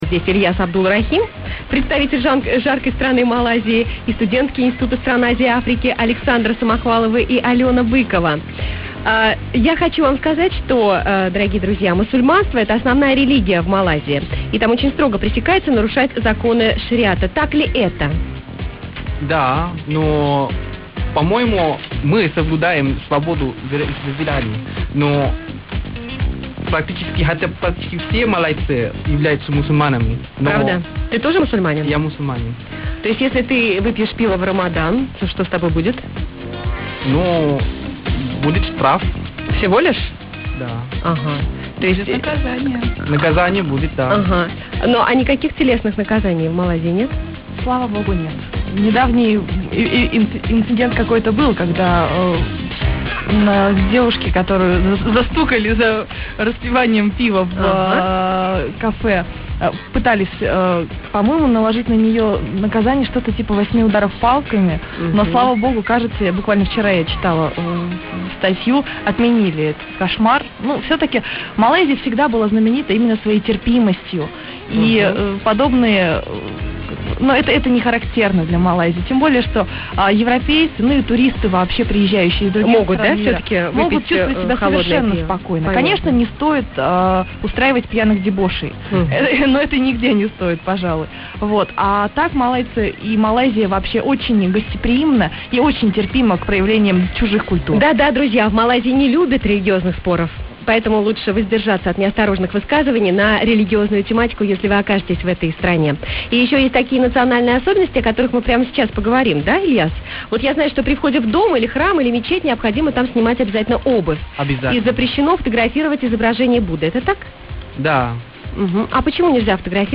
Ведущая не очень понравилась, говорила без остановки, приглашенным почти и сказать ничего не дала.